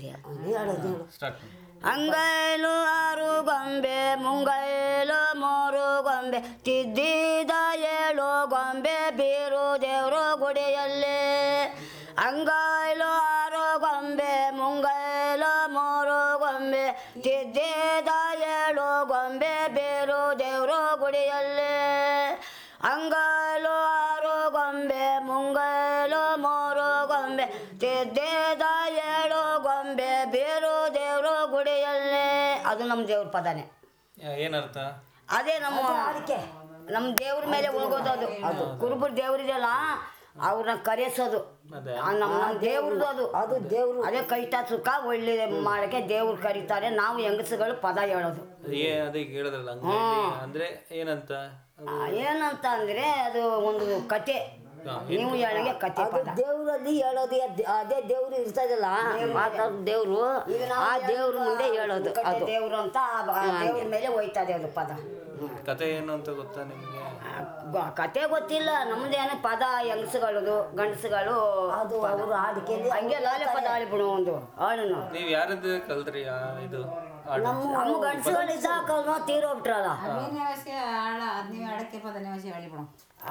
Performance of a blessing ritual song